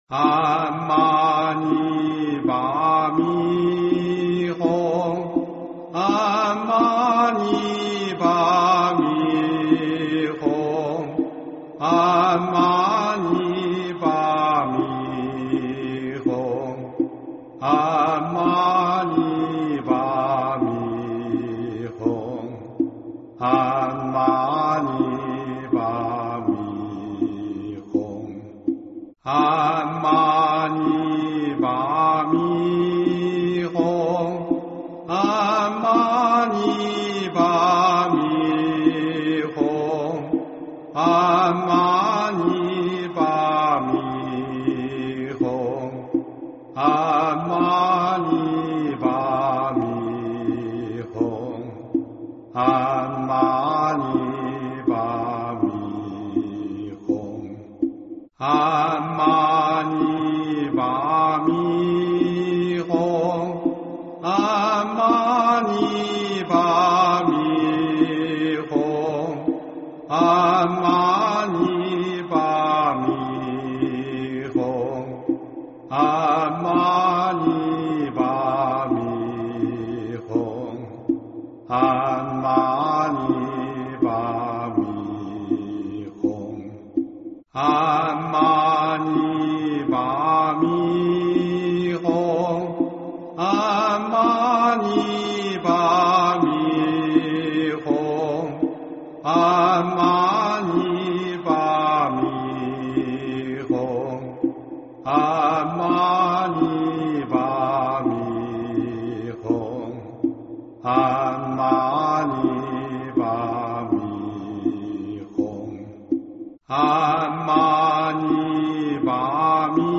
佛音 经忏 佛教音乐 返回列表 上一篇： 六字大明咒(唱诵版